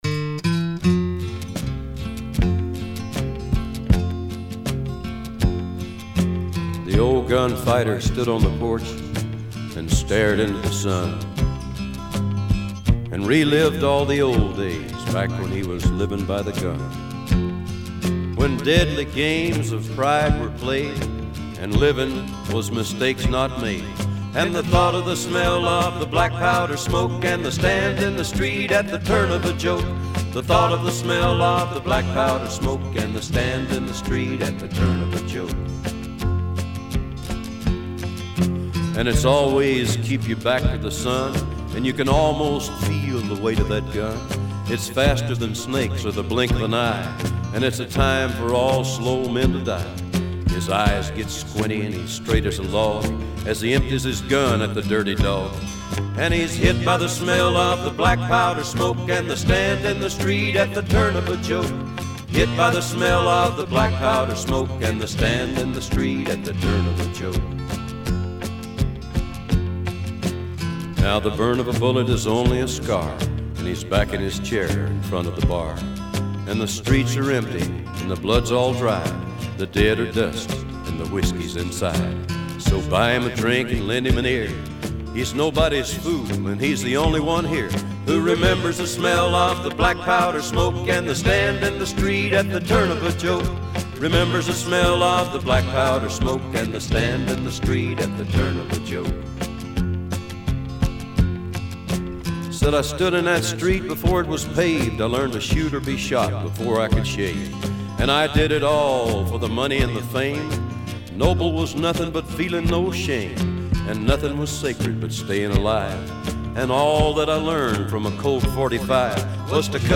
Жанр: Folk, World, & Country, Acoustic, Rock & Roll